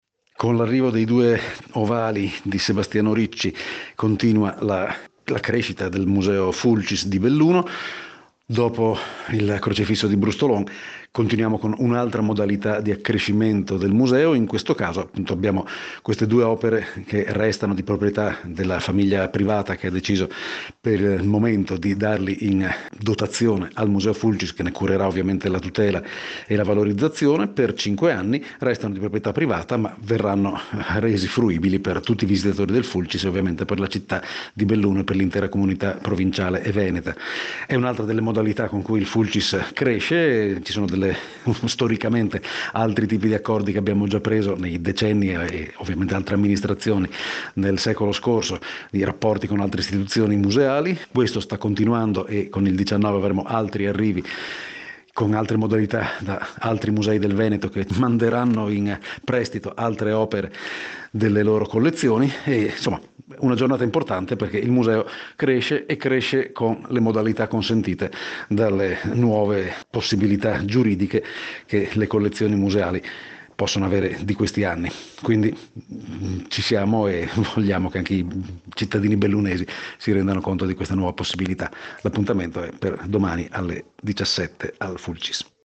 GIORNALE RADIO 09 NOVEMBRE 2018